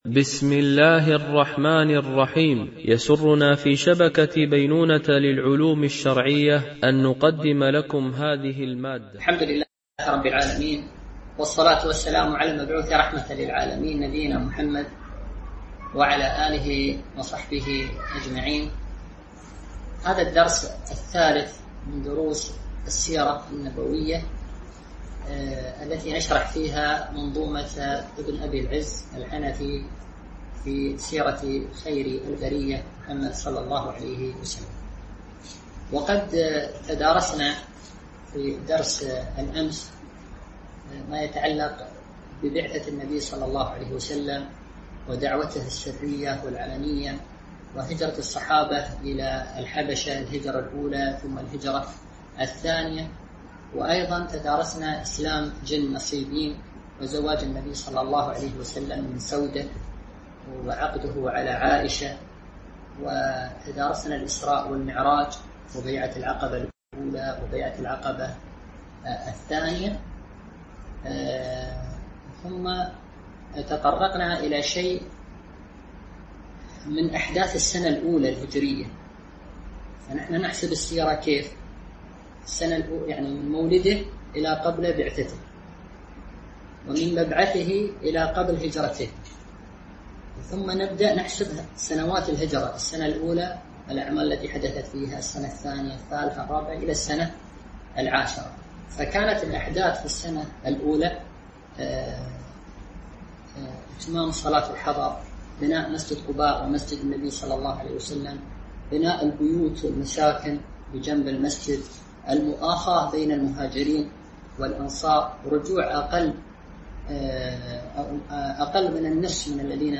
شرح الأرجوزة الميئية في ذكر حال أشرف البرية ـ الدرس 03 ( الأبيات ٤٨ - ٨٠ )